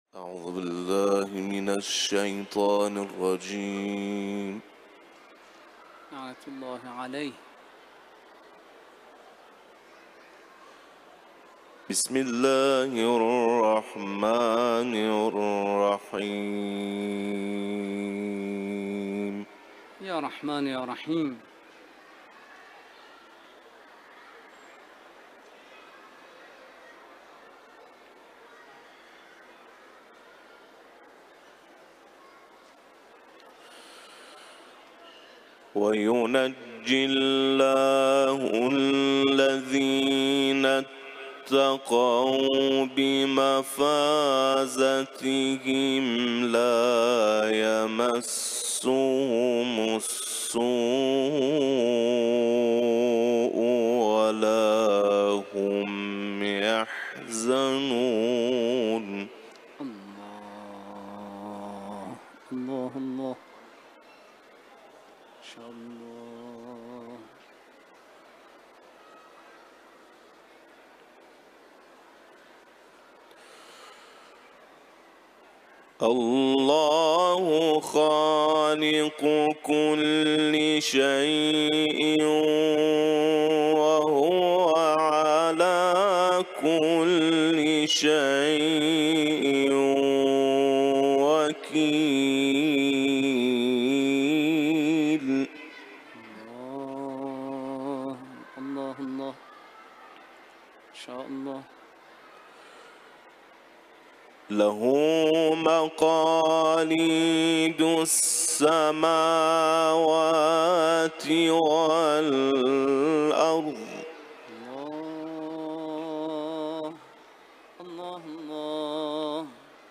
Tags: Pagbigkas ng Quran